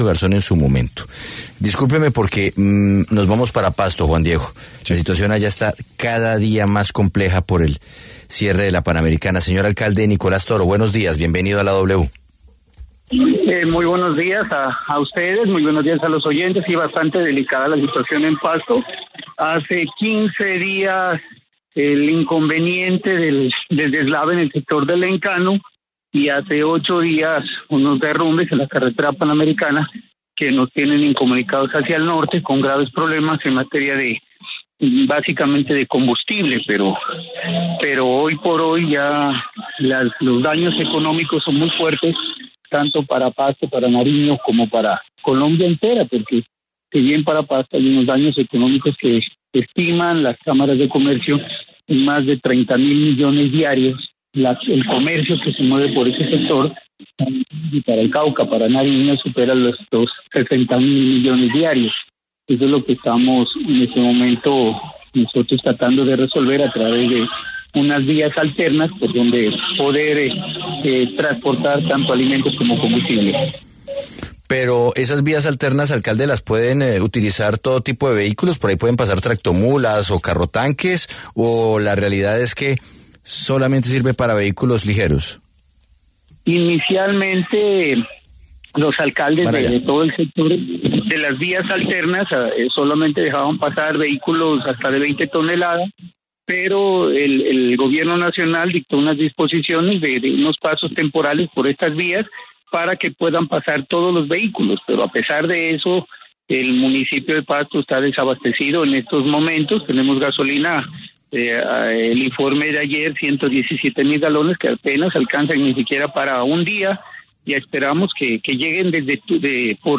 El alcalde de Pasto, Nicolás Toro Muñoz, hizo un llamado de atención en La W al Gobierno Nacional frente a la compleja situación de abastecimiento y los efectos que deja el cierre del corredor internacional en materia económica.